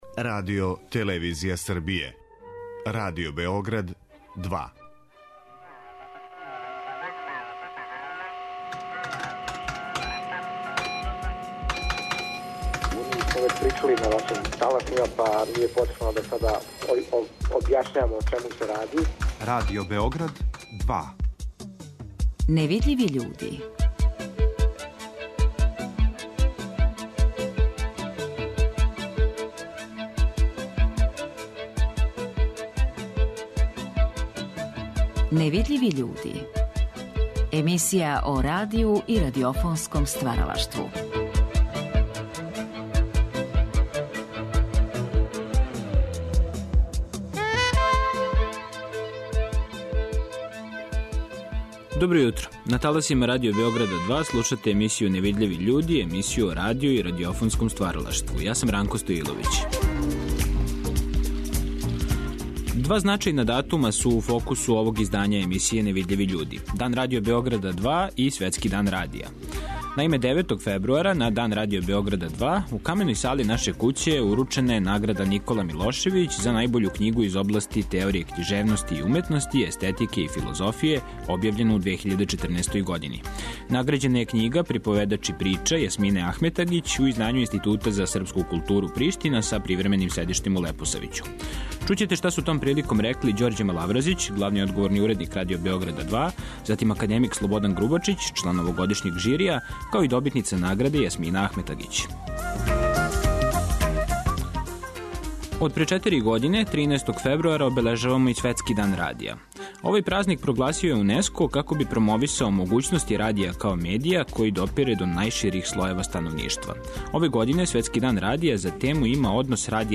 Деветог фебруара, на Дан Радио Београда 2, у Каменој сали наше куће уручена је Награда "Никола Милошевић" за најбољу књигу из области теорије књижевности и уметности, естетике и филозофије објављену у 2014. години.